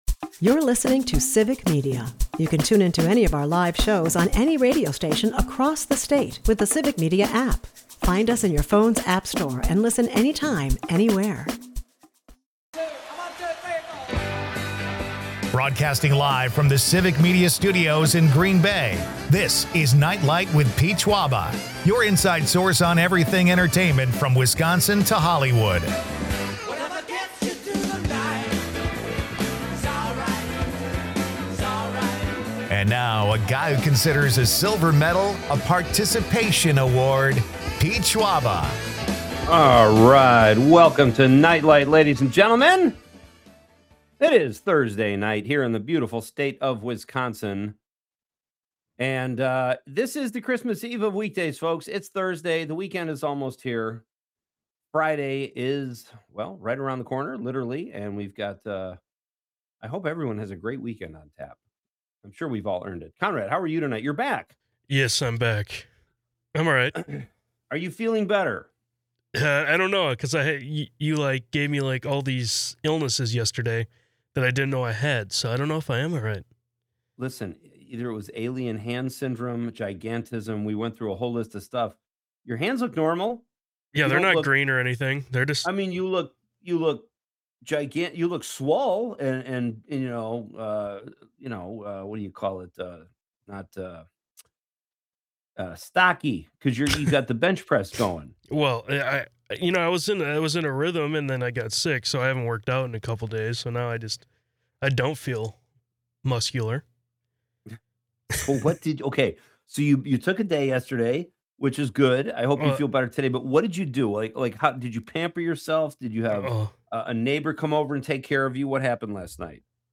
It's a whirlwind of winter fun, music, and local flavor!